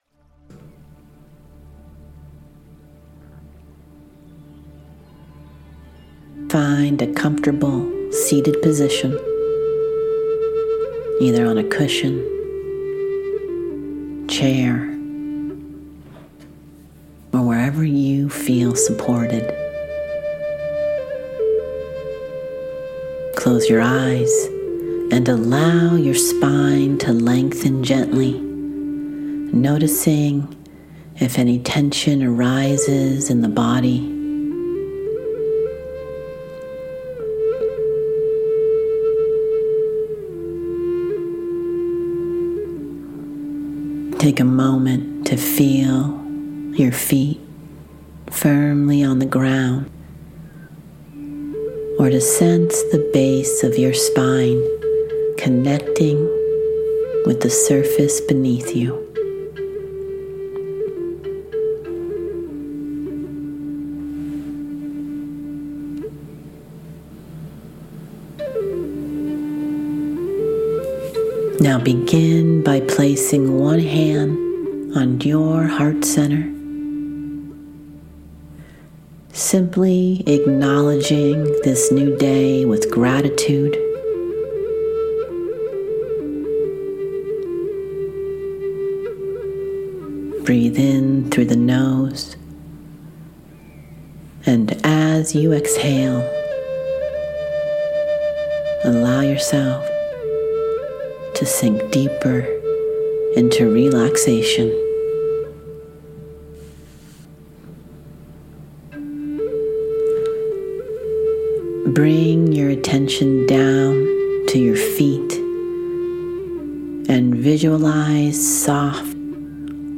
We’ll explore a guided meditation and tie it directly to the core principles that underpin professional hypnotherapy training.
Morning-Clarity-Meditation.mp3